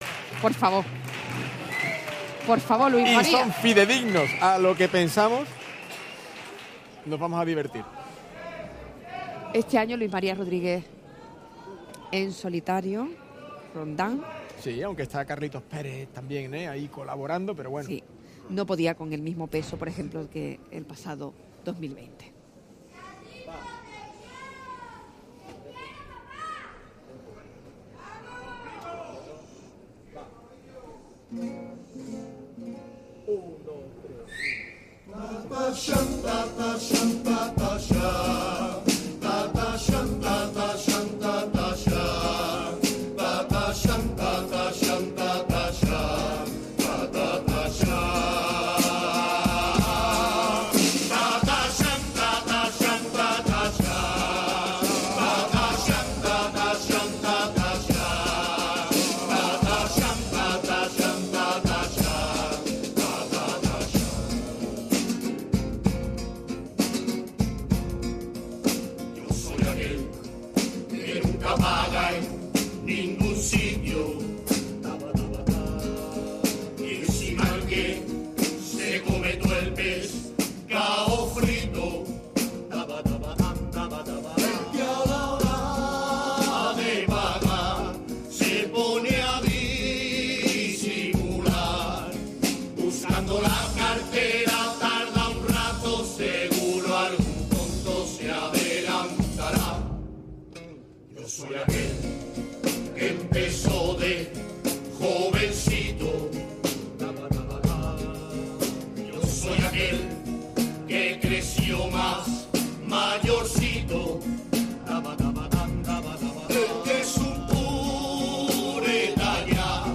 actuación